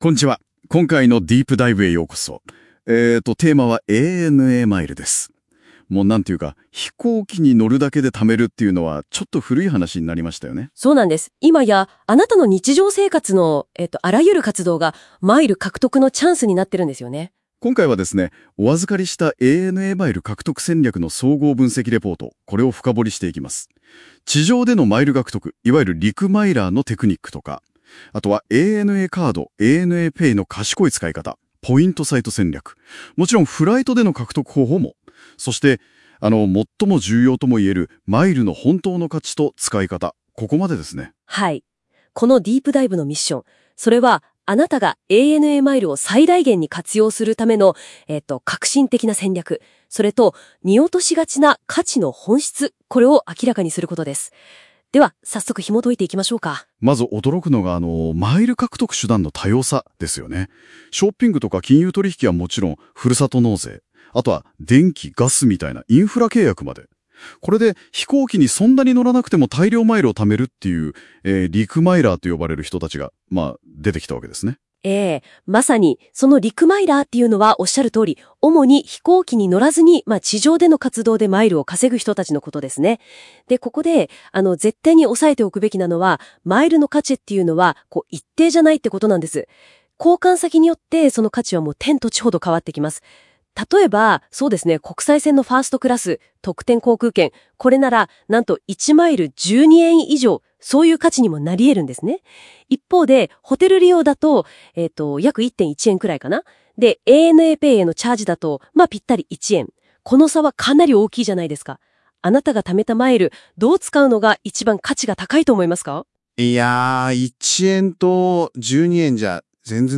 ANAマイルの貯め方やANAカードを使った支払いやマイルの効率の良い貯め方について深掘りしていくラジオです。